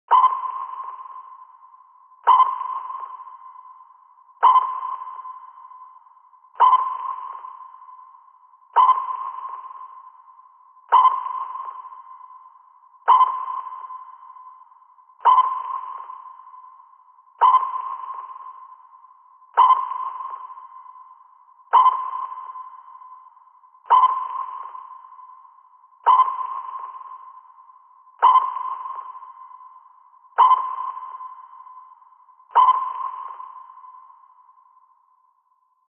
Звуки сонара подводной лодки
На этой странице собраны звуки сонаров подводных лодок — от монотонных импульсов до сложных эхолокационных сигналов.